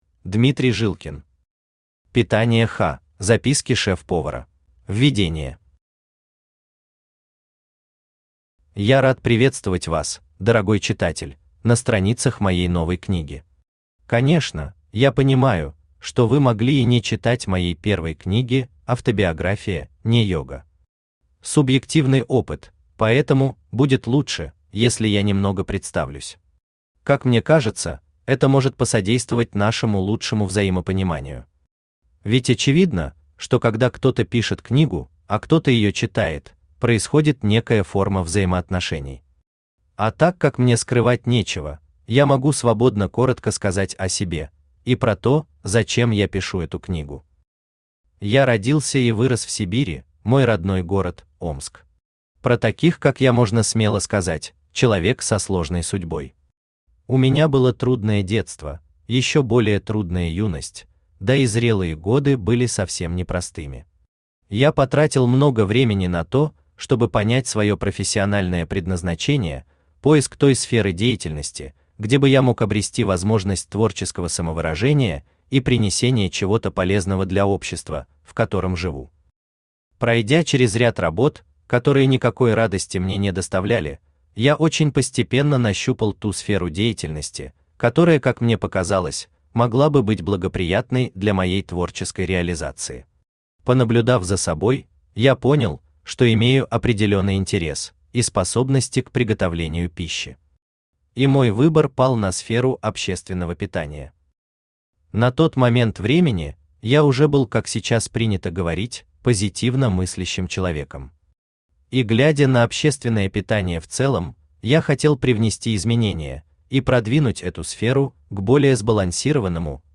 Аудиокнига Питание-Х. Записки Шеф-повара | Библиотека аудиокниг
Aудиокнига Питание-Х. Записки Шеф-повара Автор Дмитрий Николаевич Жилкин Читает аудиокнигу Авточтец ЛитРес.